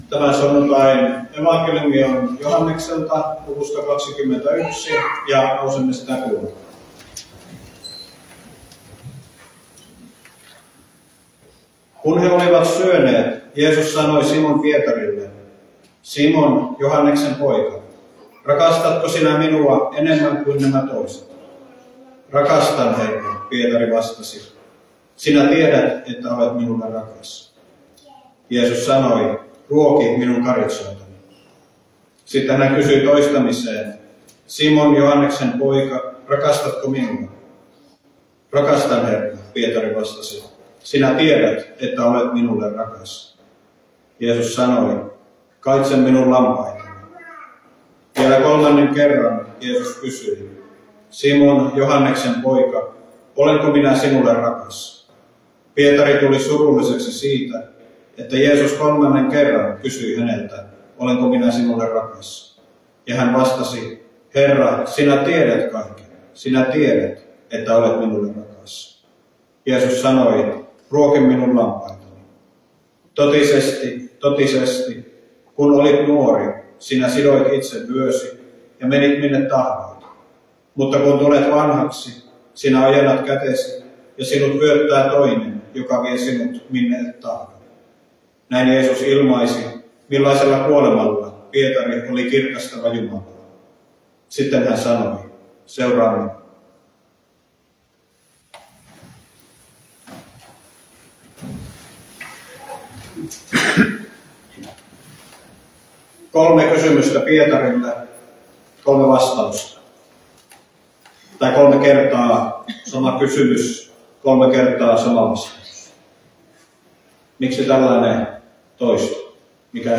saarna